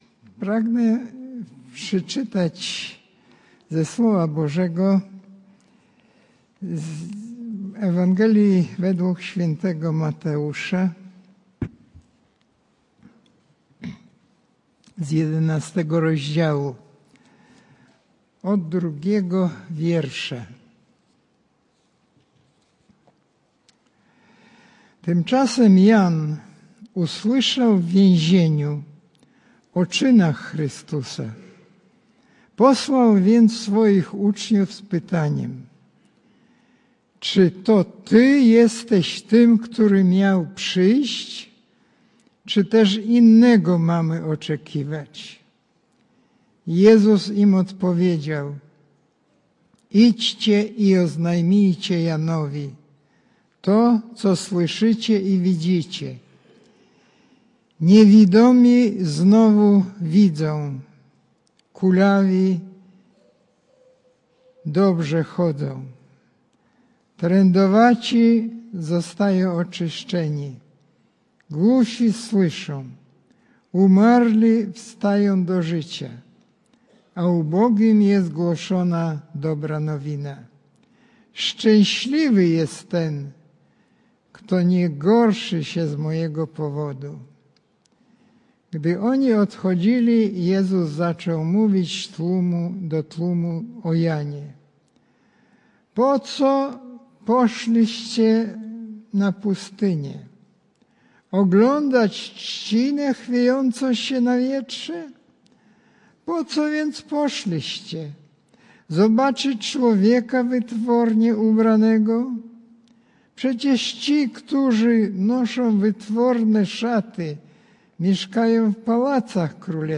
Passage: Ewangelia Mateusza 11, 2 - 11 Kazanie
wygłoszone na nabożeństwie w niedzielę 5 stycznia 2025 r.